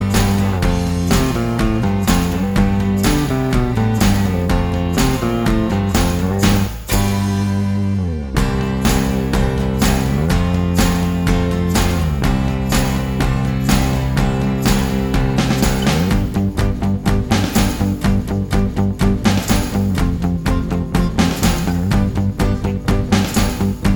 Minus Guitar Solo Pop (1980s) 2:47 Buy £1.50